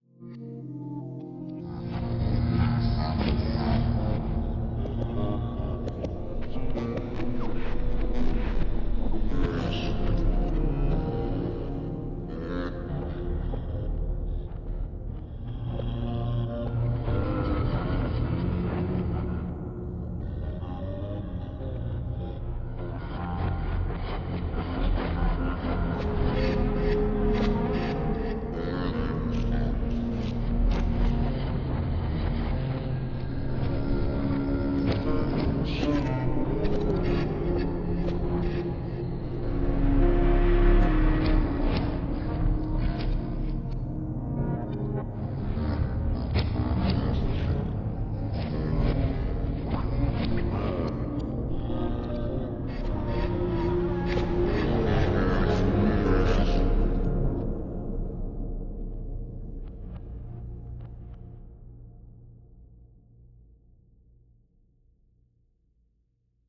It takes a directory of audio files, chops it, shuffles it, and frankensteins it up into a single audio file according to your BPM, effects and other settings.
Random full length songs.